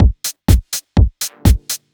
Original creative-commons licensed sounds for DJ's and music producers, recorded with high quality studio microphones.
techno arp future fiction.wav